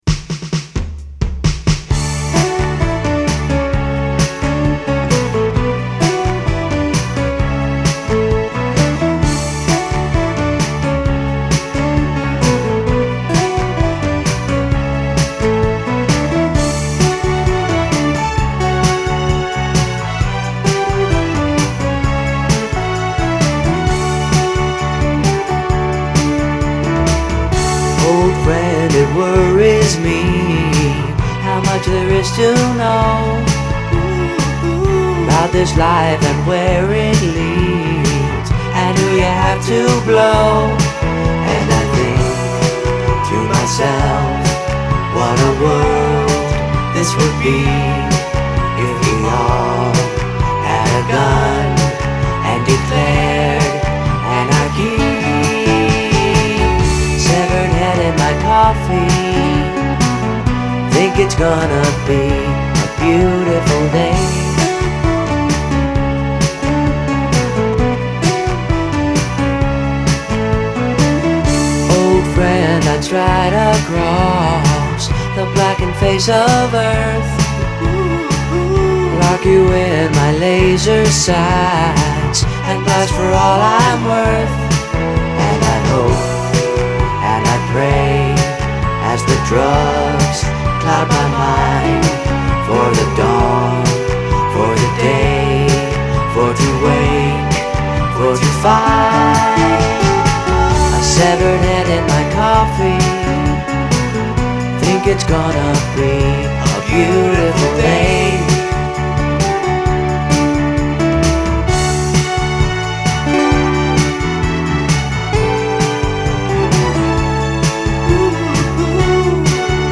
sings the lovely backing vocals